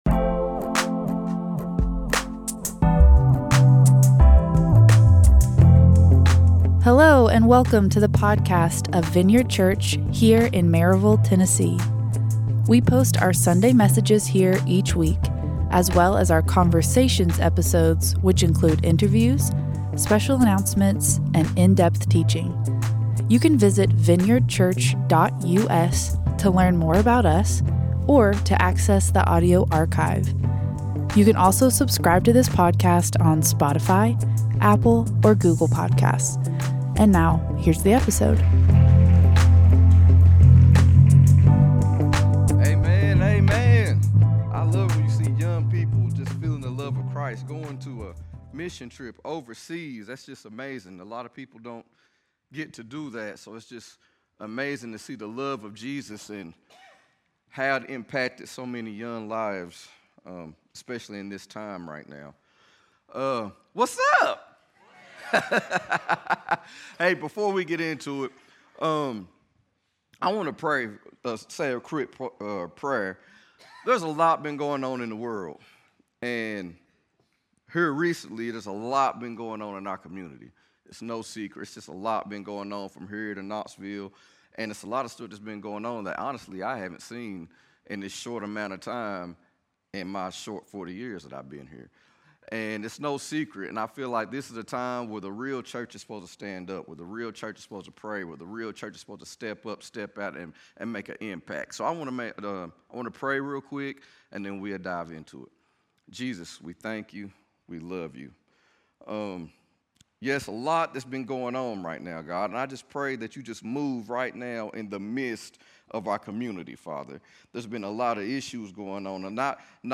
A message from the series "What Realm Are You In?."